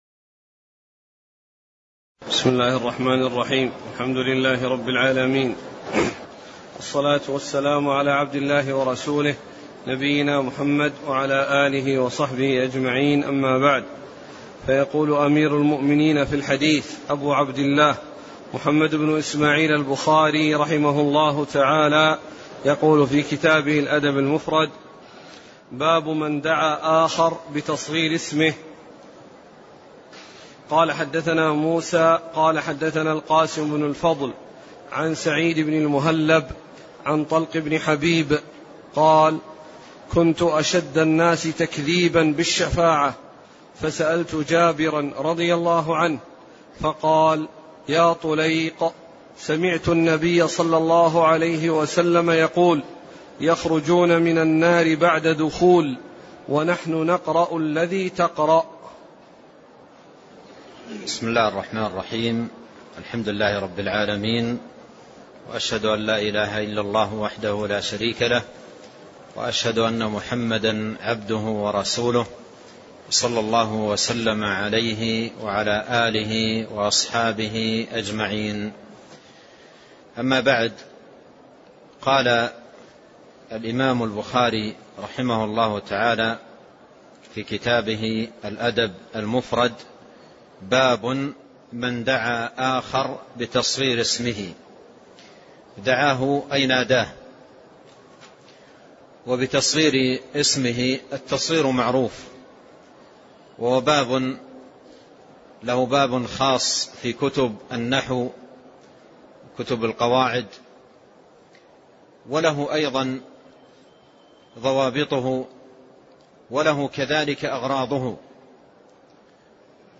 المكان: المسجد النبوي الشيخ: فضيلة الشيخ عبد الرزاق بن عبد المحسن البدر فضيلة الشيخ عبد الرزاق بن عبد المحسن البدر حديث: (كنت أشدّ الناس تكذيبا بالشفاعة)